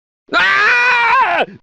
Patchwork Pig Screaming Sound Effect Download: Instant Soundboard Button